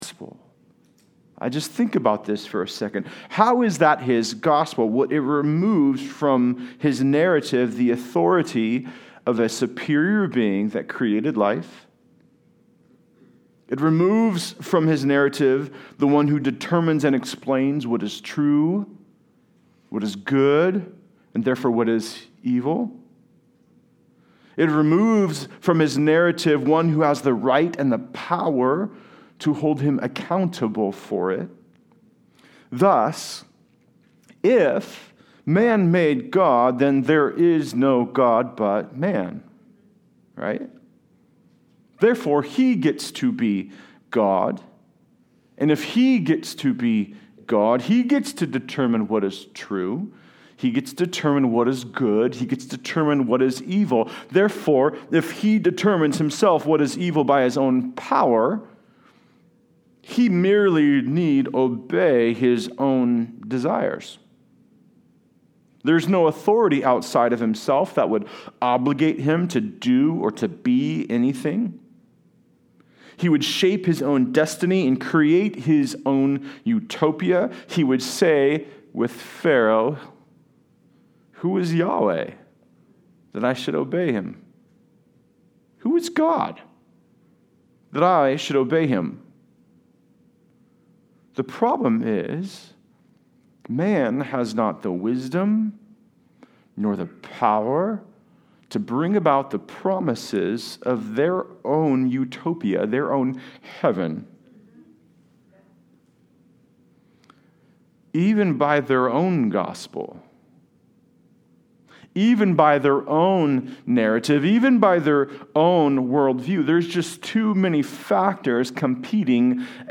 I AM THE LORD Passage: Exodus 8:16-9:12 Service Type: Sunday Service Related « God Is Always At Work!